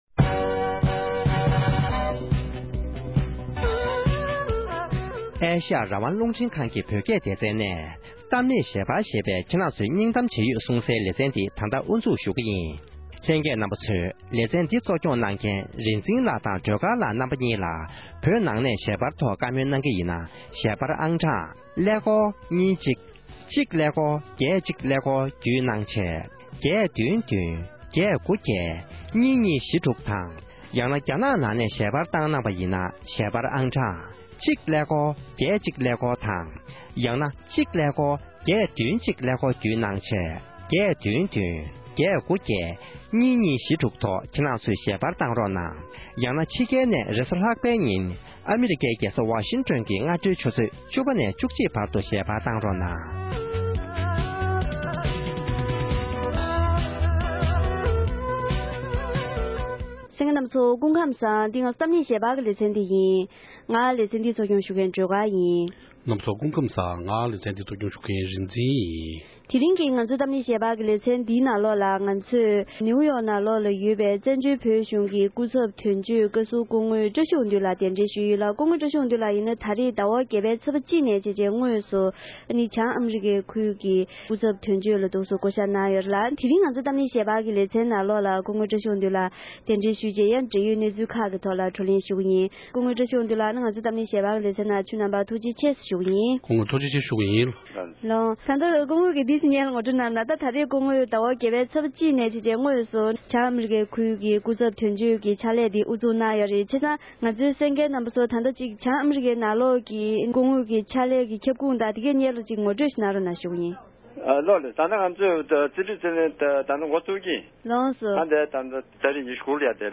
The Call-In Show